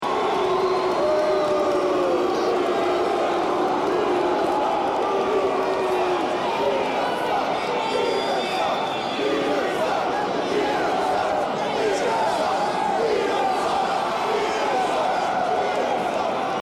No footage of this match was known to exist until, three decades later, a fan recording was uploaded by a YouTube channel whose name escapes me.
These post-match antics didn’t do him any favors with the crowd, who chanted “
peter-sucks.mp3